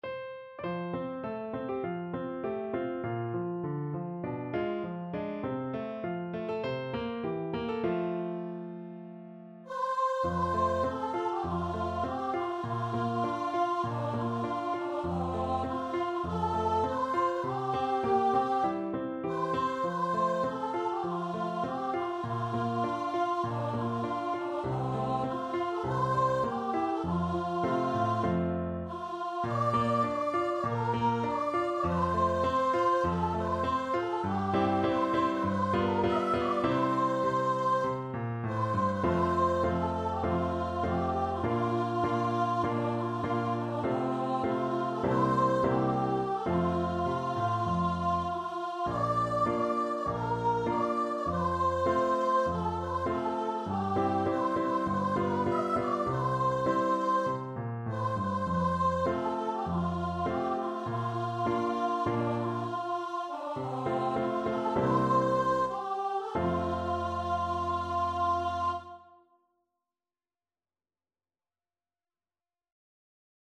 Voice
4/4 (View more 4/4 Music)
F major (Sounding Pitch) (View more F major Music for Voice )
~ = 100 Moderato
Traditional (View more Traditional Voice Music)